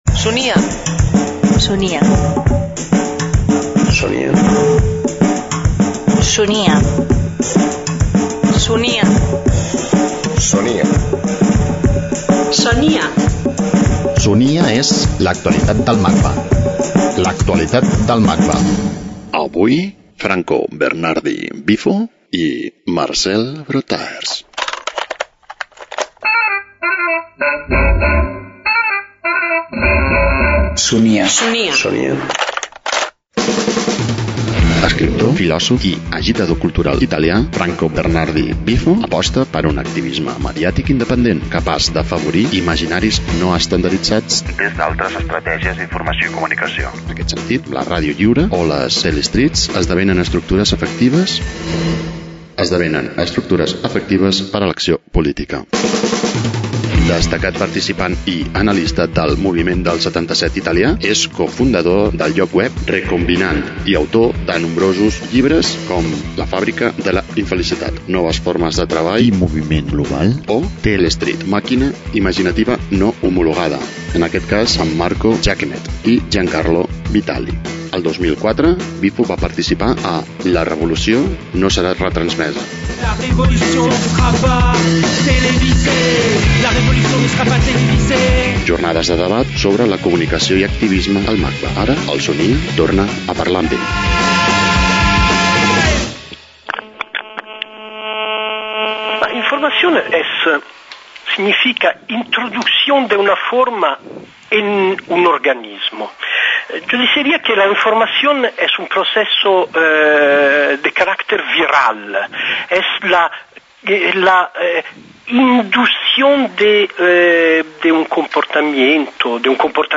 e0ef01106fc59877bcfa4f8269bd4d4d003b9acb.mp3 Títol Ràdio Web MACBA Emissora Ràdio Web MACBA Titularitat Tercer sector Tercer sector Cultural Nom programa Son[i]a. L'actualitat del MACBA Descripció Primera edició del programa. Indicatiu del programa, invitat, indicatiu, presentació i explicacions de Franco Berardi "Bifo" i fragment de l'obra sonora de Marcel Broodthaers "Entrevista a un gat" de 1970